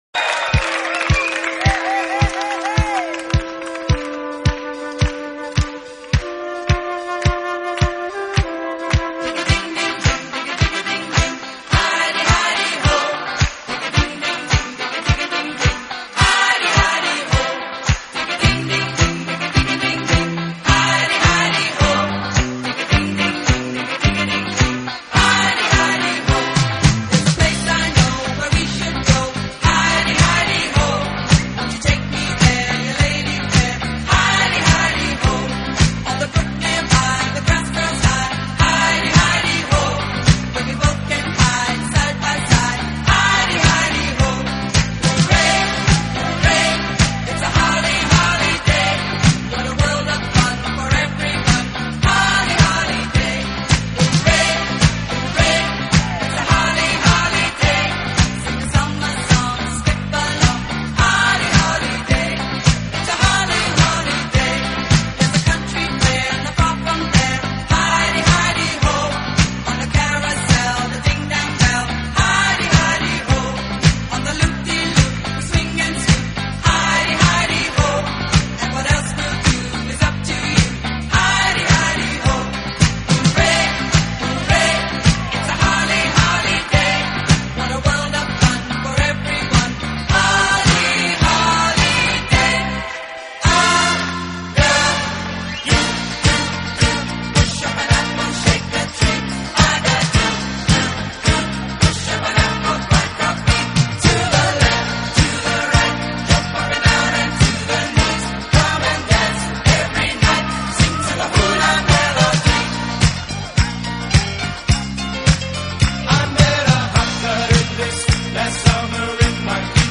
成，都是联唱式的集成曲。